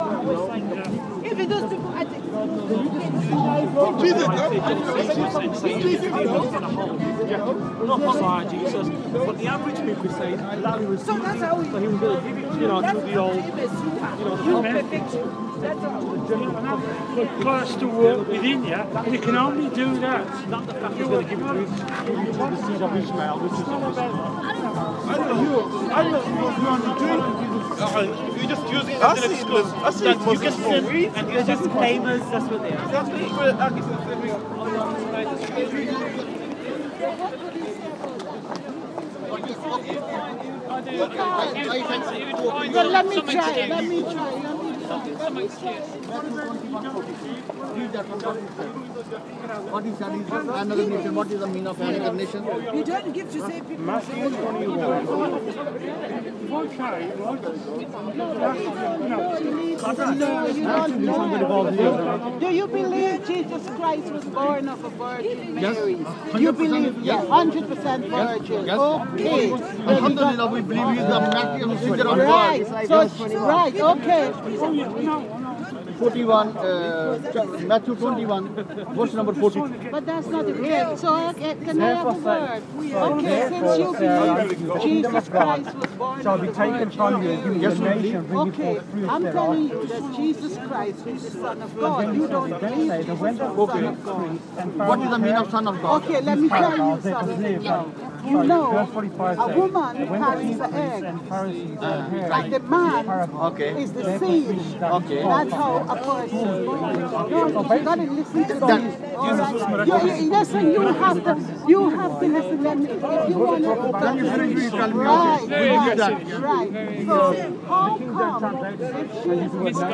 A mixed gathering of Christian and Muslim individuals have a number of simultaneous theological and philosophical discussions on High Street in Birmingham.